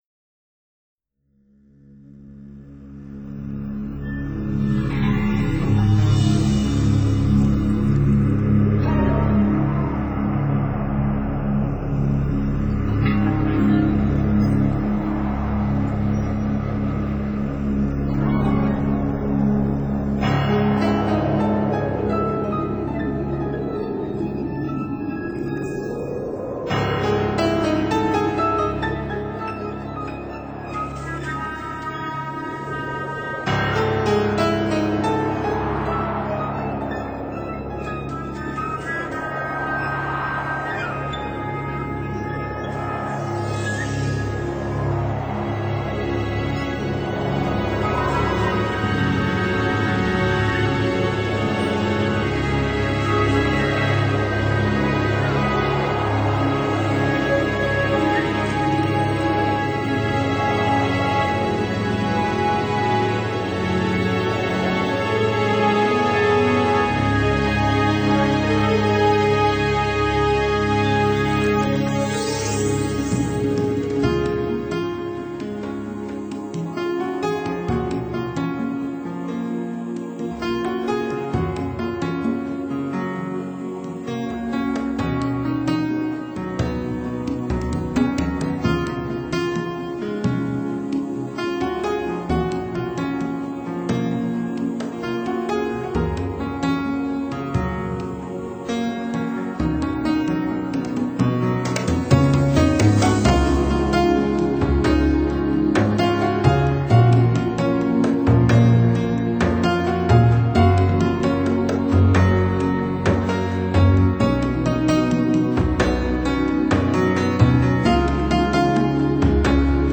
☆音乐风格的层次鲜活多变，峰回路转，有柳暗花明又一春的开阔心境。